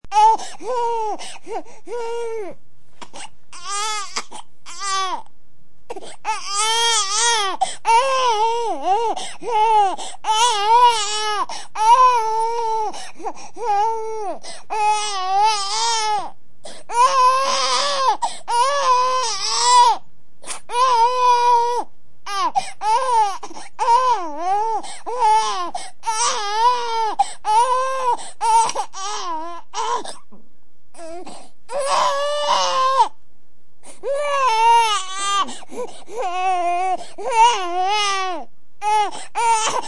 Download Crying Baby sound effect for free.
Crying Baby